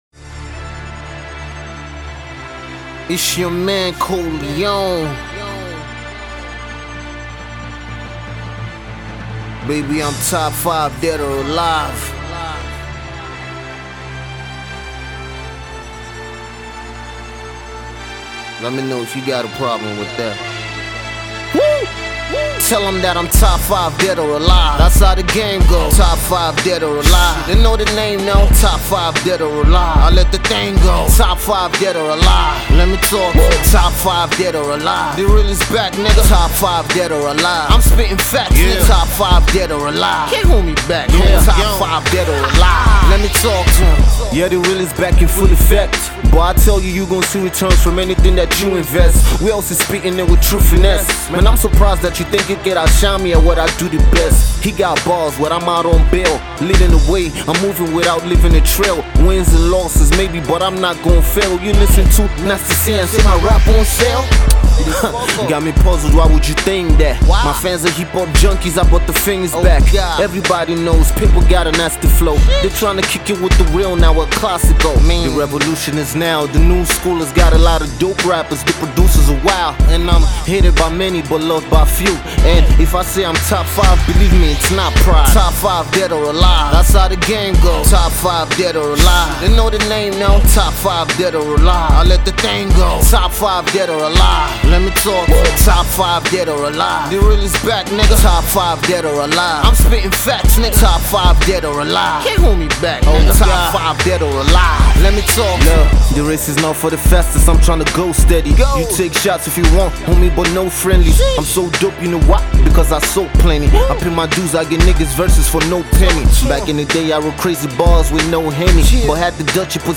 fast-rising rap act